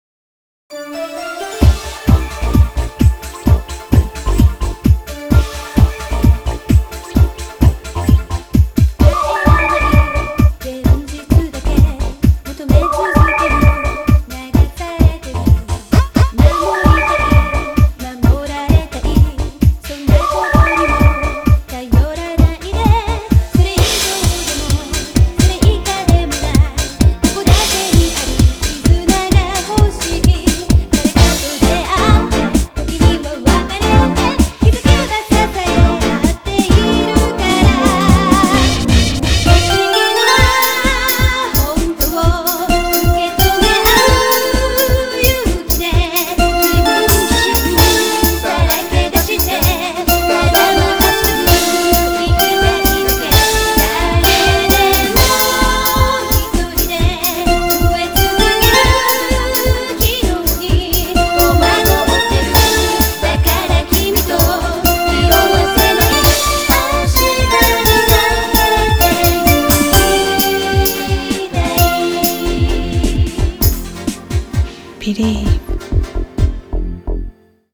BPM130
Audio QualityPerfect (High Quality)
J-Pop